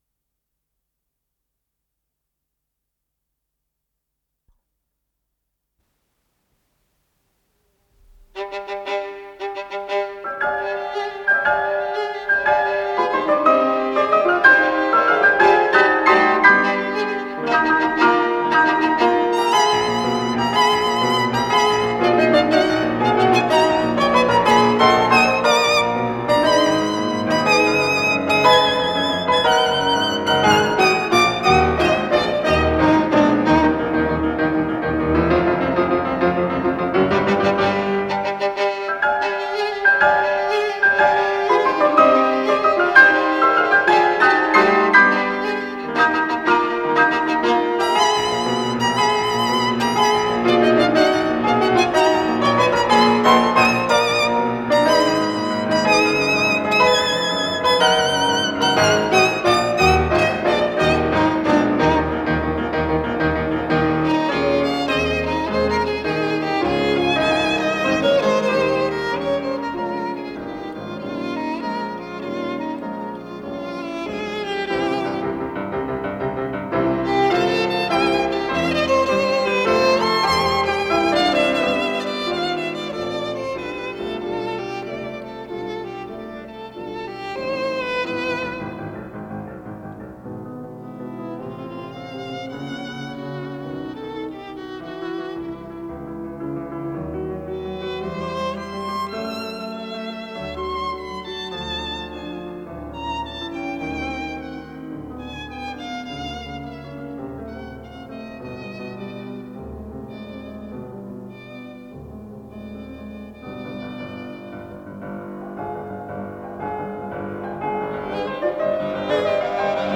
с профессиональной магнитной ленты
скрипка
фортепиано
ВариантДубль моно